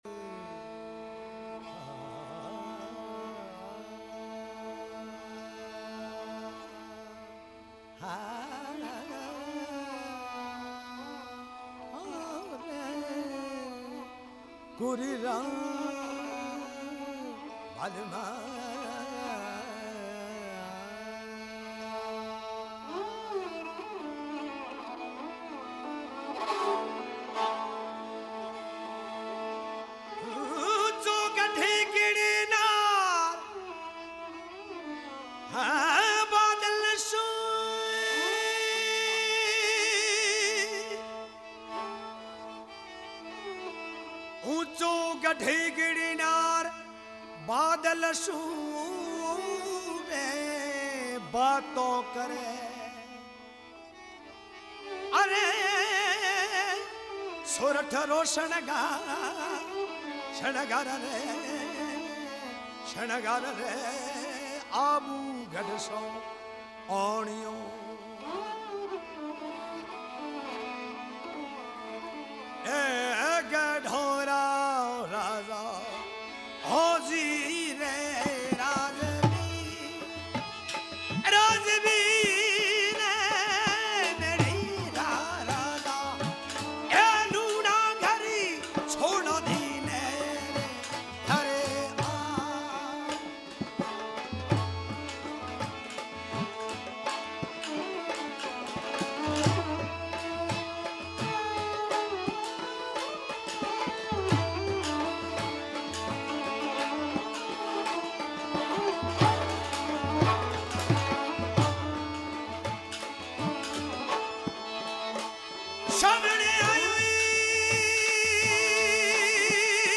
Lyrics: Traditional